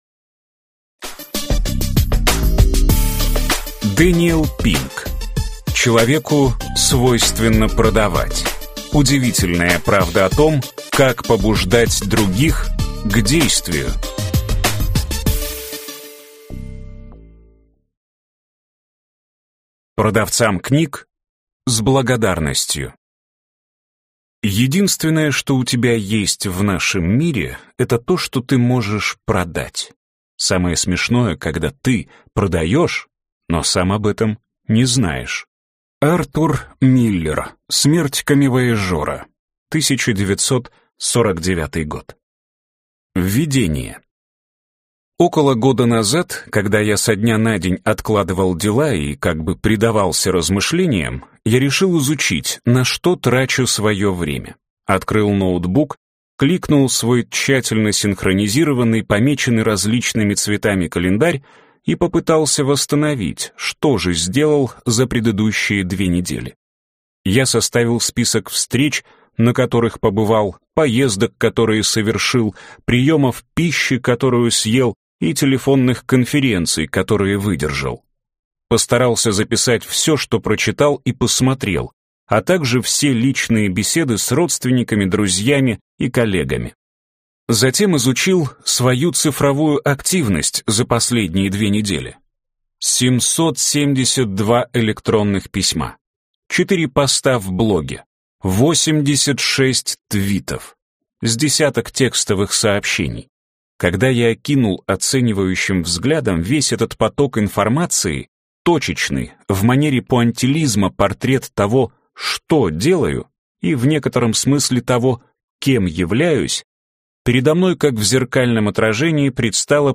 Аудиокнига Человеку свойственно продавать. Удивительная правда о том, как побуждать других к действию | Библиотека аудиокниг